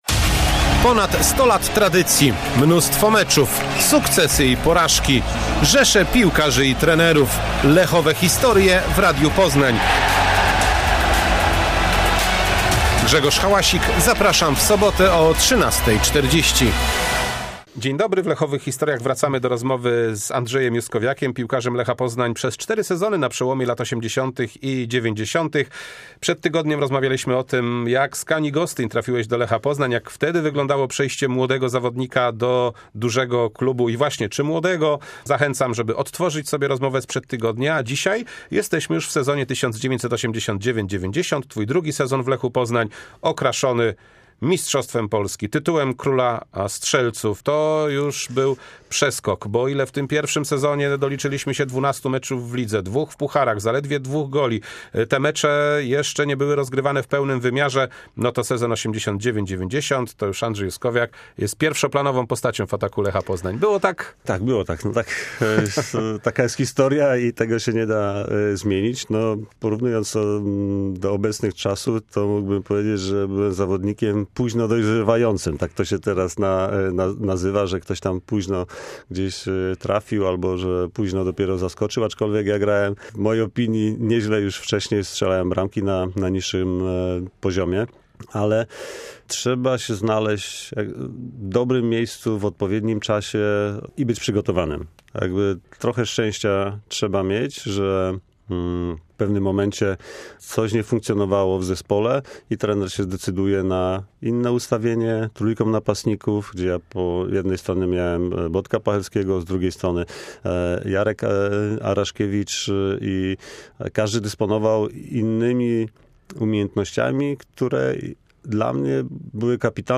W 39.odcinku Lechowych historii druga część rozmowy z Andrzejem Juskowiakiem.